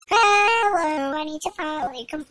Tags: auto tune